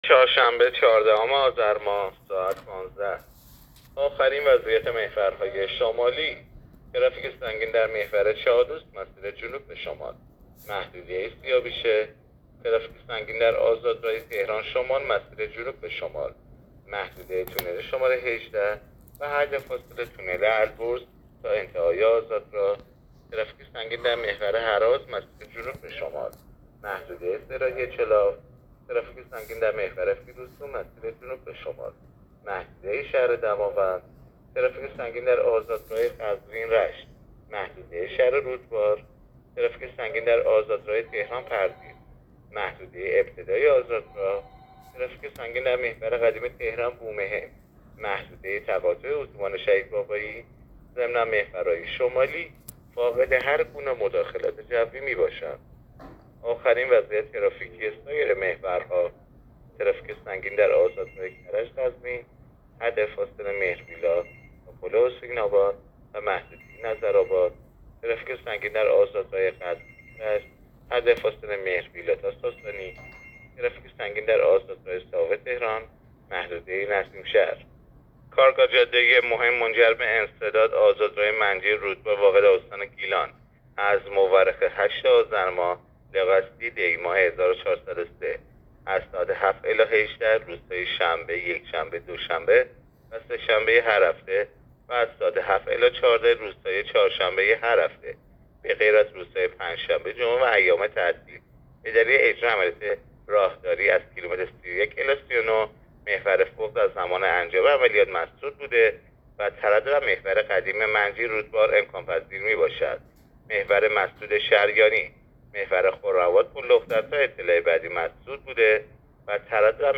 گزارش رادیو اینترنتی از آخرین وضعیت ترافیکی جاده‌ها تا ساعت ۱۵ چهاردهم آذر؛